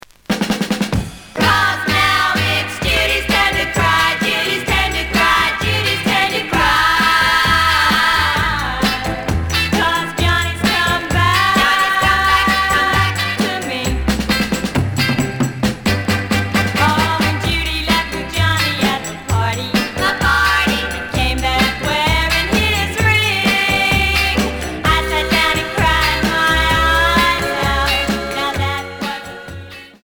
The audio sample is recorded from the actual item.
●Genre: Rock / Pop
Some click noise on beginning of both sides.